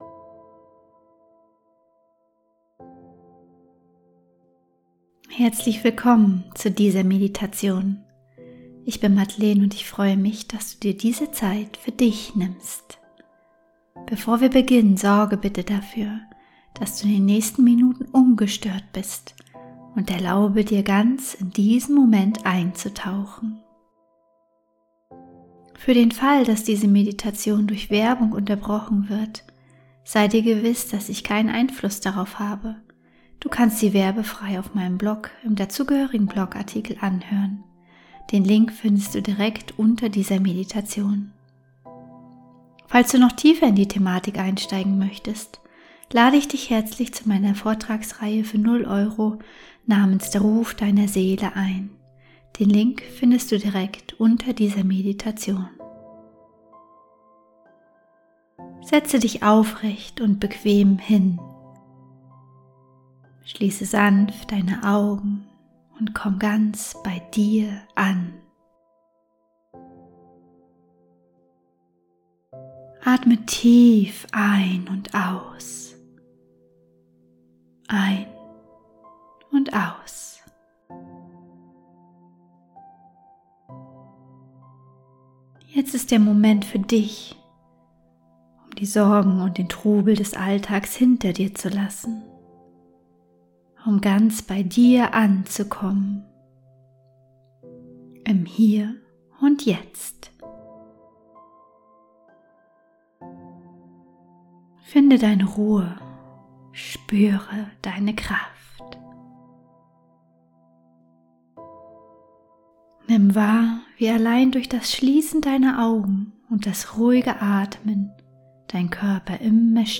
Willkommen zu dieser 12-minütigen geführten Meditation.